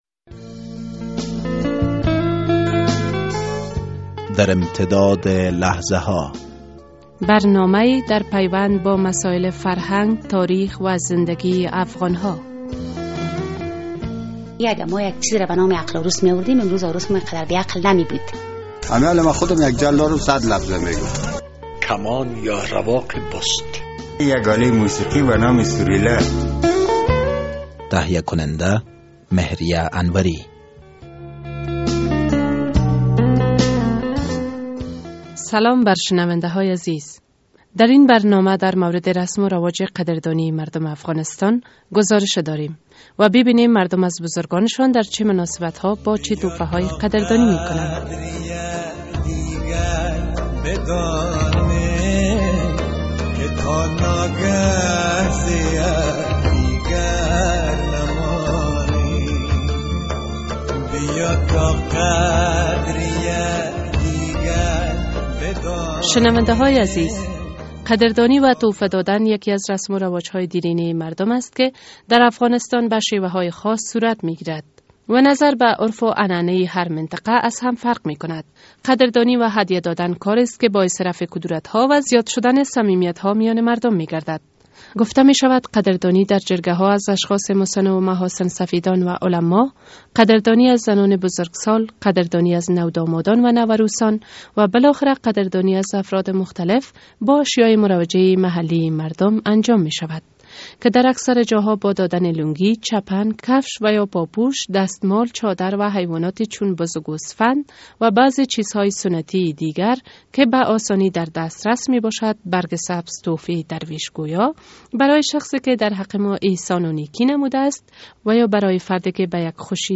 در این برنامه در مورد رسم و رواج قدردانی مردم افغانستان گزارشی تهیه شده است. در این گزارش خواهید شنید که مردم از بزرگان شان در چه مناسبت ها با چی تحایفی قدردانی می کنند...